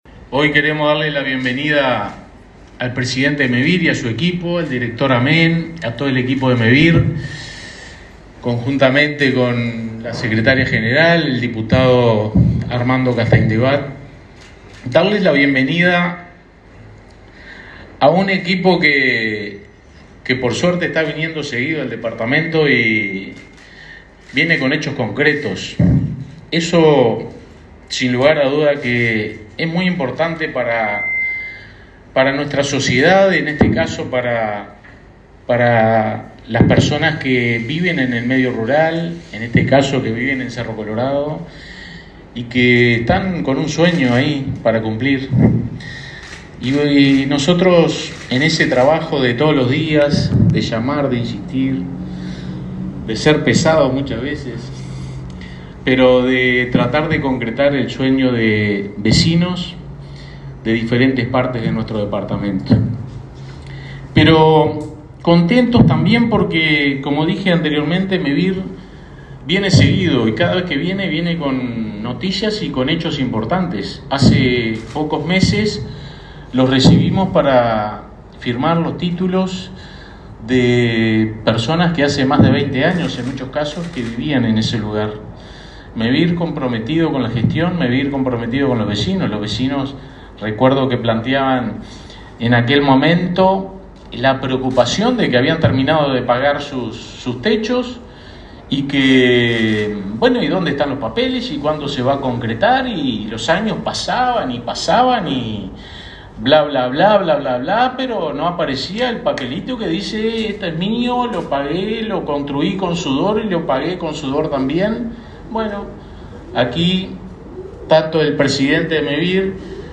Palabras de autoridades en Flores
Palabras de autoridades en Flores 02/02/2023 Compartir Facebook X Copiar enlace WhatsApp LinkedIn Mevir y la Intendencia de Flores lanzaron un programa nuevo de viviendas en Cerro Colorado. El intendente Fernando Echeverría y el presidente de Mevir, Juan Pablo Delgado, destacaron la importancia del plan.